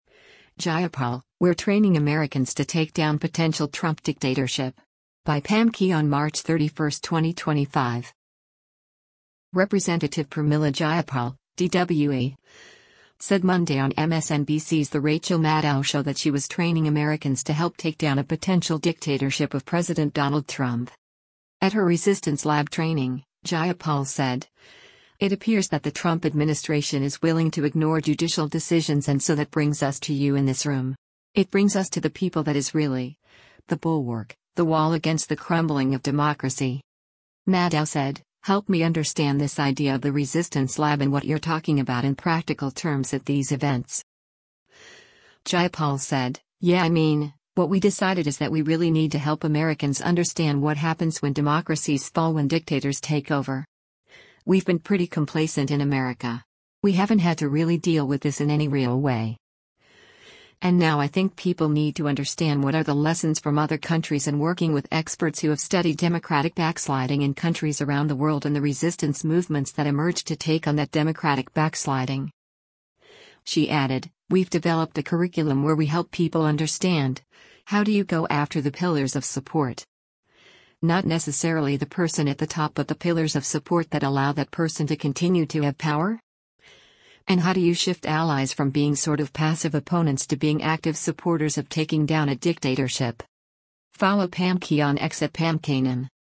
Representative Pramila Jayapal (D-WA) said Monday on MSNBC’s “The Rachel Maddow Show” that she was training Americans to help take down a potential “dictatorship” of President Donald Trump.